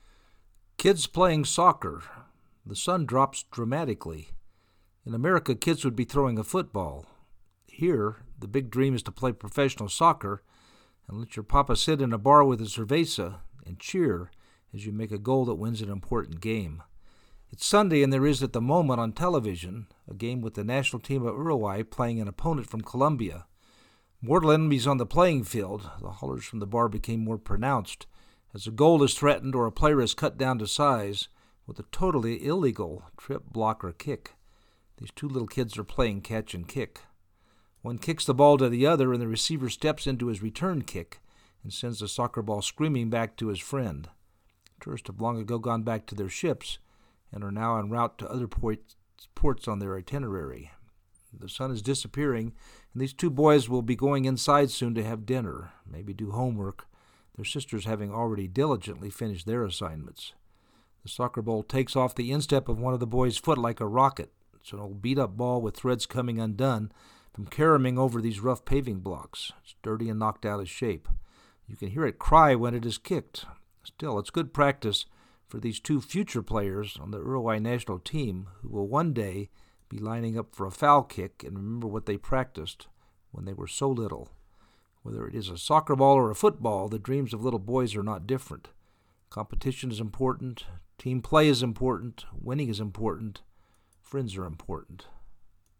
These two little kids are playing catch and kick. One kicks the ball to the other and the receiver steps into his return kick and sends the soccer ball screaming back to his friend.
It is an old beat up ball with threads coming undone from caroming over these rough paving blocks.
You can hear it cry when it is kicked.
kids-playing-soccer-4.mp3